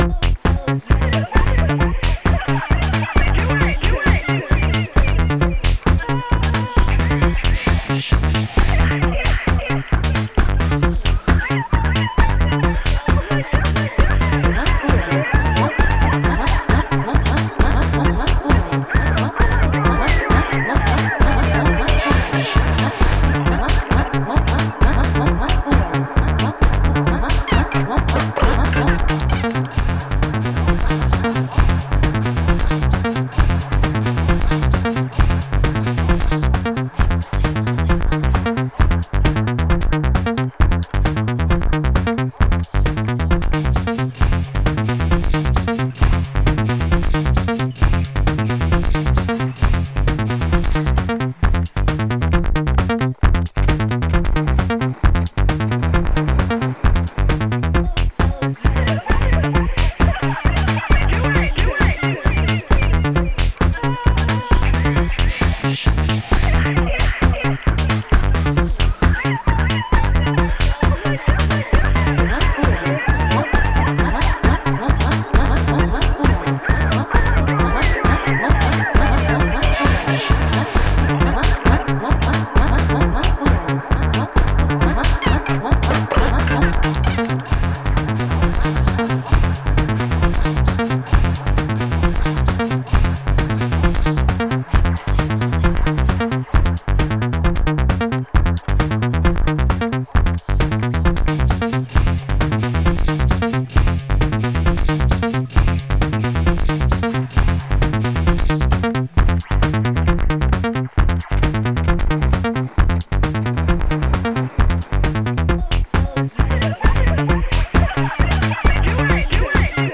Genre: EBM, IDM.